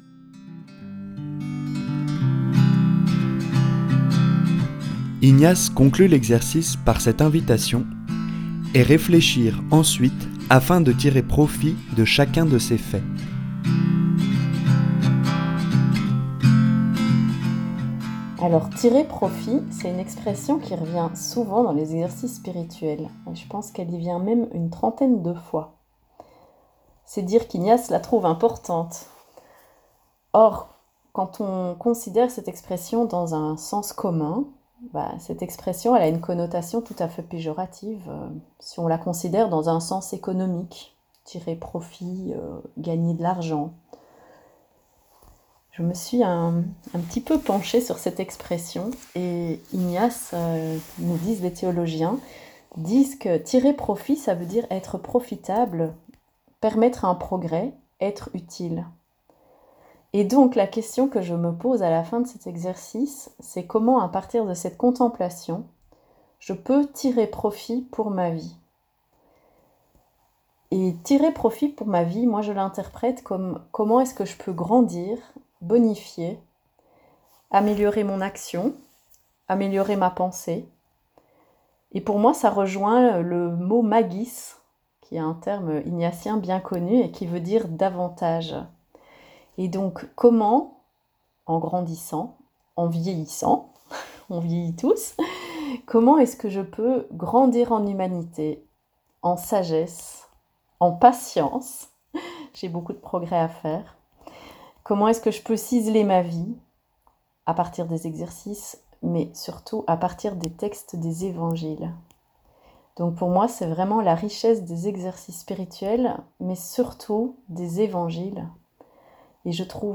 Contempler comment Dieu s’engage dans notre monde – le témoignage
> Crédits musicaux  : « Prière MEJ », Mouvement Eucharistique Jeunesse ; Auteur : Jean Latapie sj ; Compositeur original : Olivier Oger & [No Copyright Music] Woods – Acoustic Folk Background Music